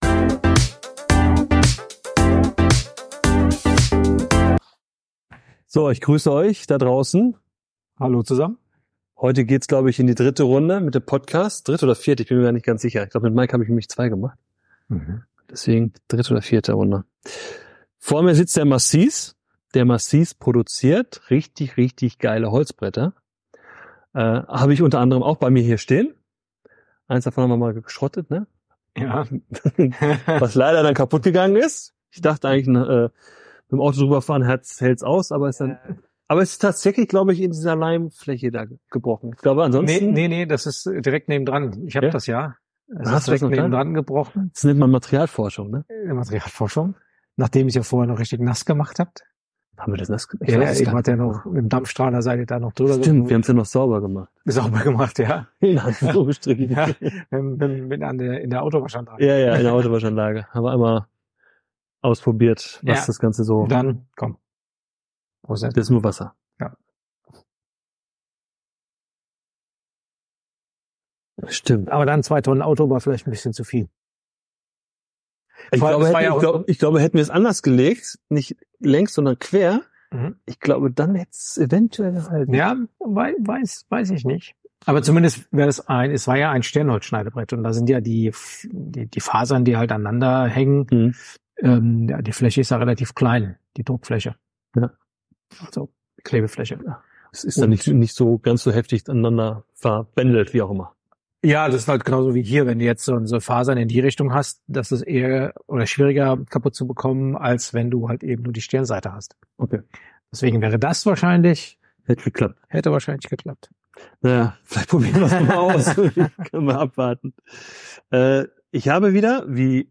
DIE NORMALOS PODCAST Luxus trifft Handwerk: Exklusive Holzbretter für die Küche – Im Gespräch mit "Ruhrholz Manufaktur"